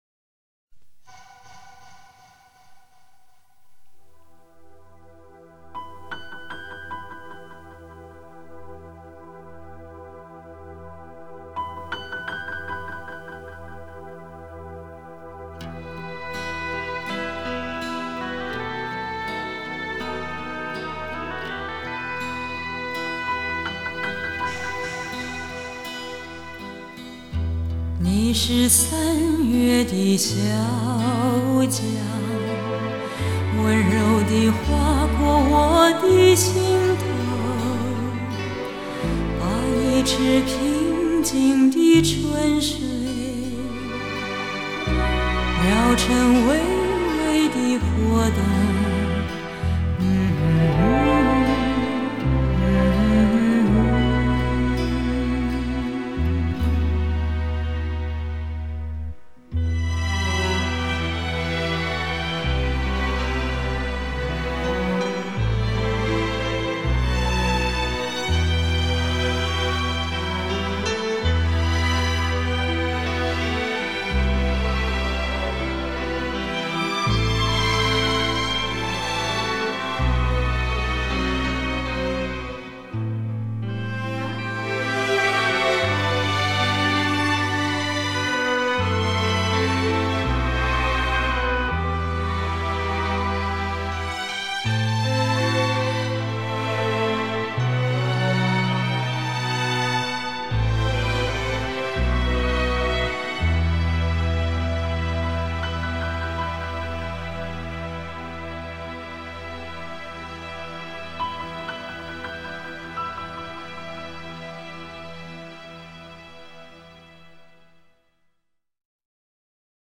淳朴的声音，简单的吉他